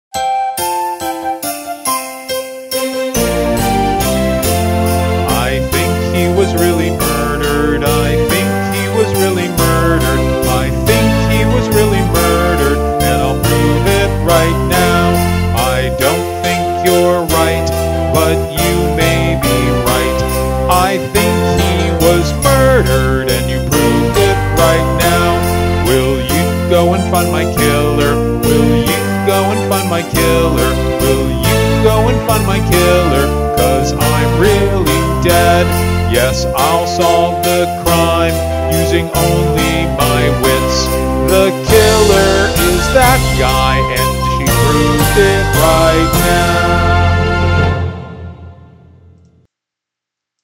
I Think He Was Really Murdered Guide Vocal